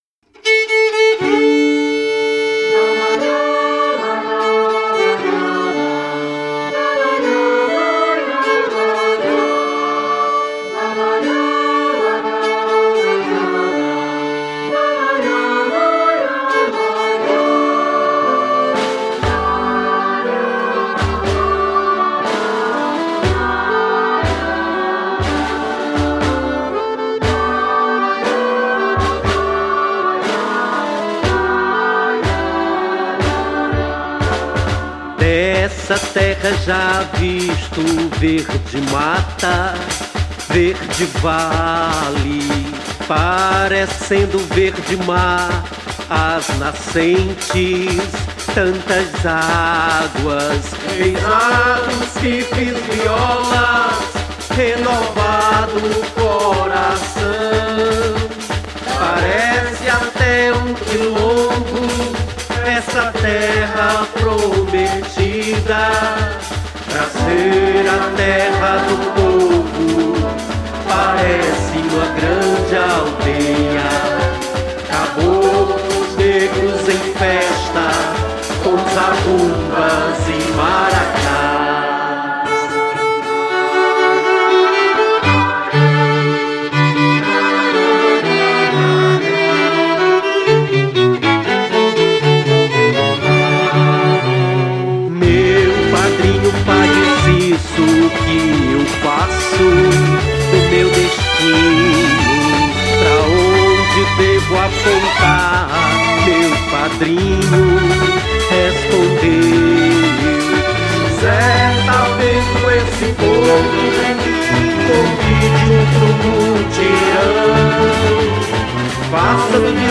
Gênero: Regional